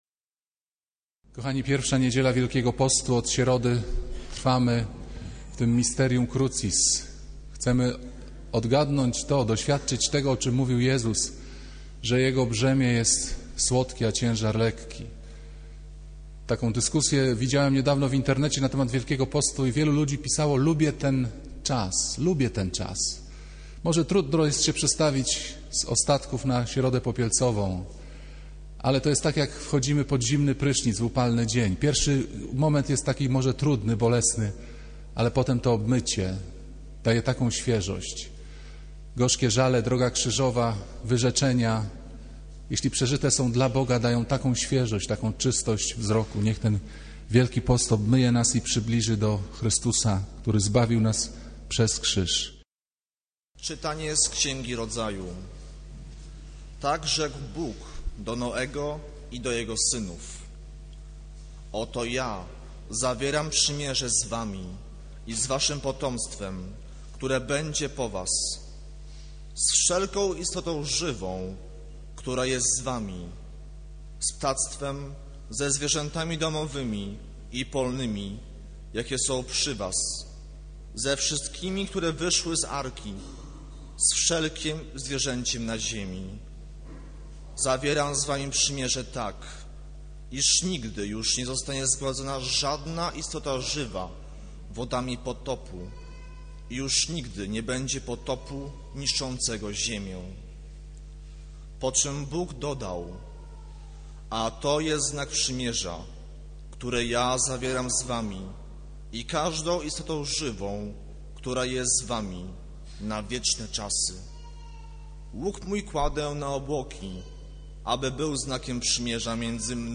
Kazanie z 9 sierpnia 2009r.